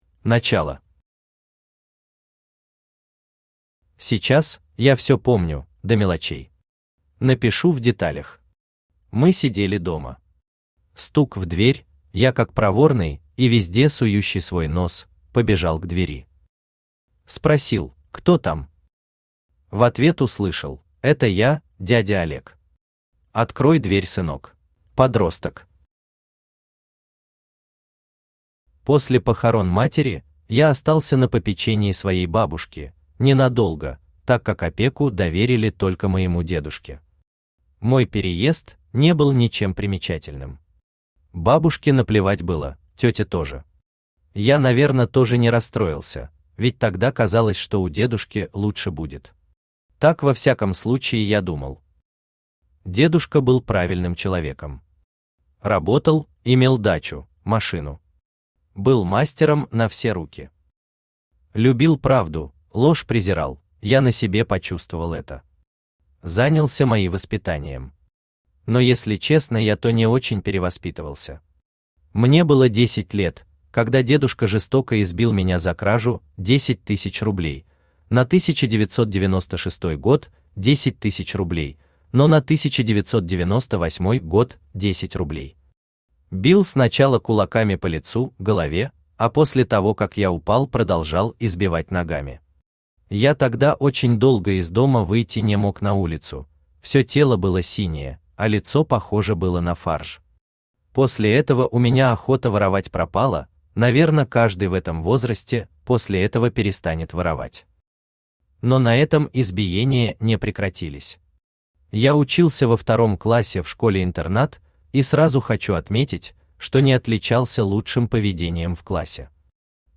Аудиокнига Сирота | Библиотека аудиокниг
Aудиокнига Сирота Автор Сергей Викторович Глухарёв Читает аудиокнигу Авточтец ЛитРес.